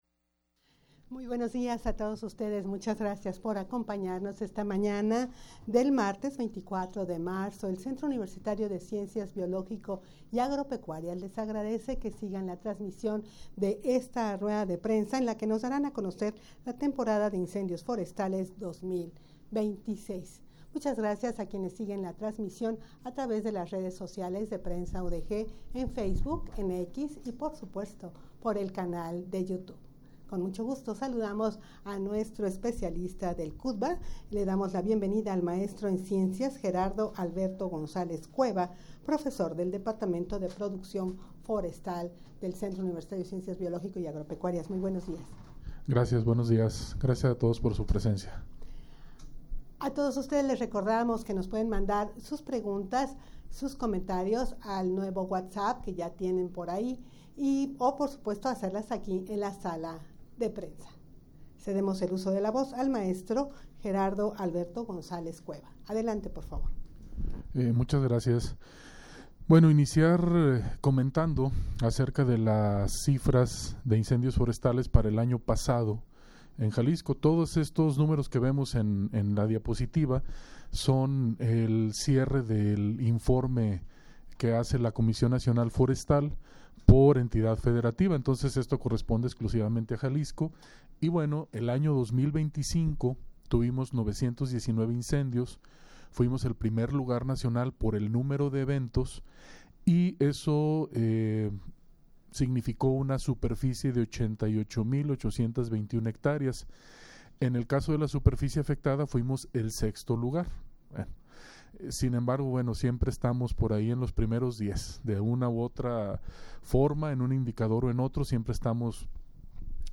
rueda-de-prensa-para-dar-a-conocer-temporada-de-incendios-forestales-2026.mp3